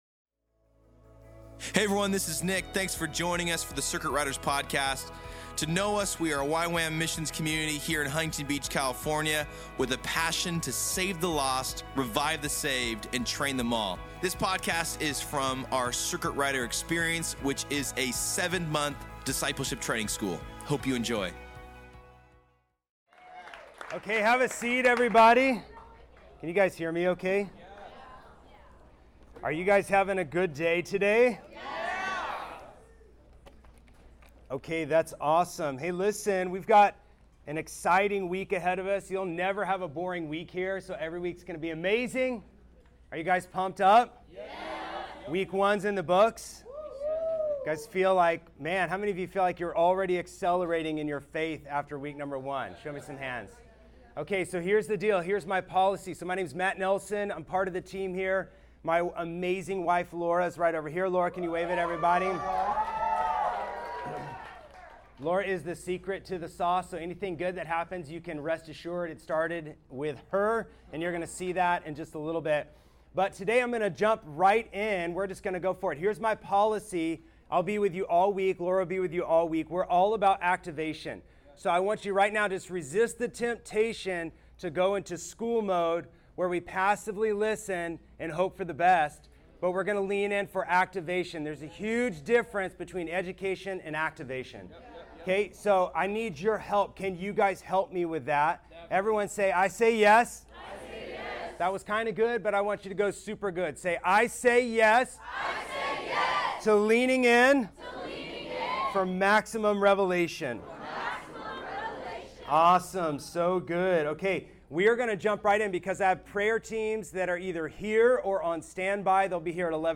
to the 2023 Fall CR Experience.